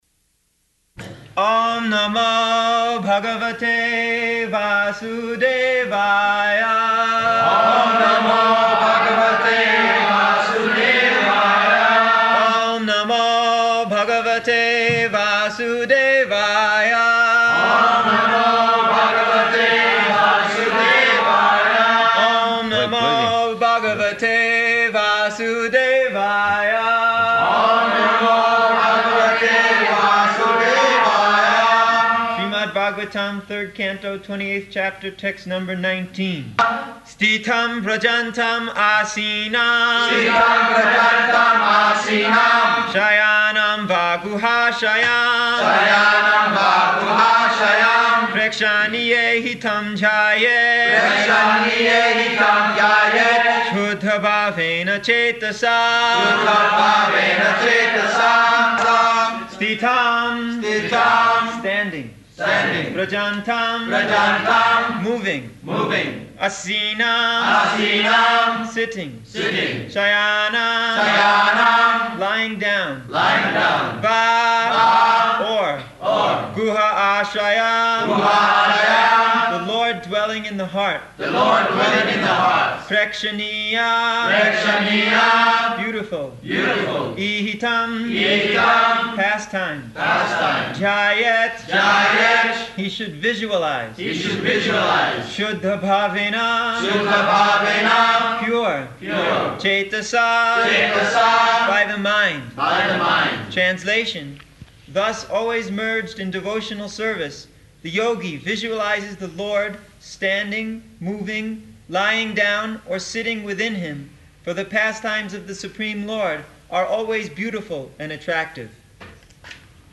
-- Type: Srimad-Bhagavatam Dated: October 29th 1975 Location: Nairobi Audio file
[devotees repeat] Śrīmad-Bhāgavatam, Third Canto, Twenty-eighth Chapter, text number nineteen.